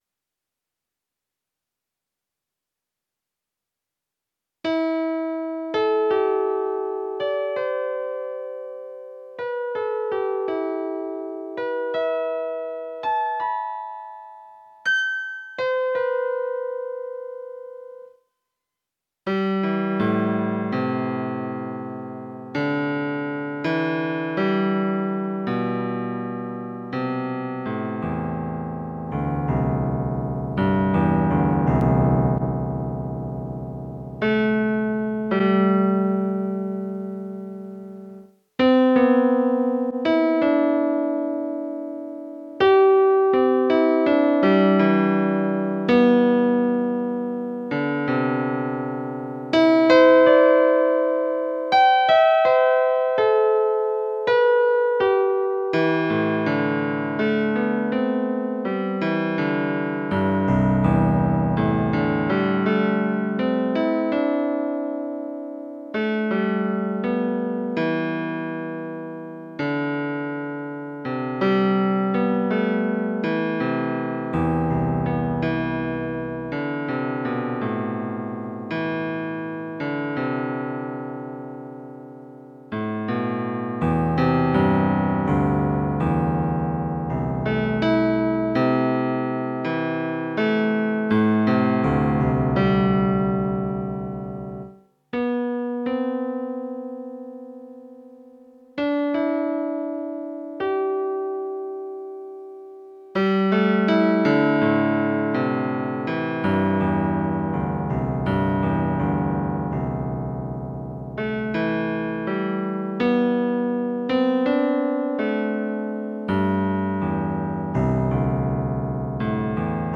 Enregistré aux Eyzies, vallée de la Vézère – le 02 novembre 2025.
La captation musicale du Chêne de la Vallée éternelle.